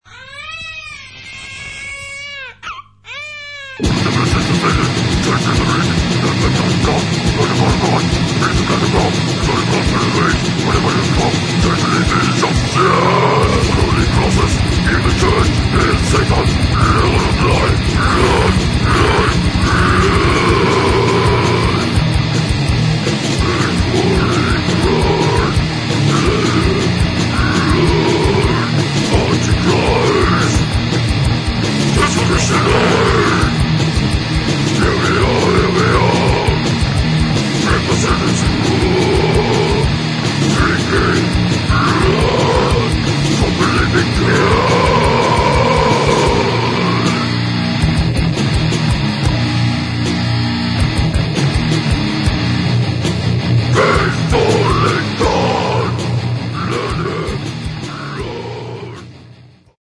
Брутальный Death Metal.
Некоторое влияние Crust и Grind.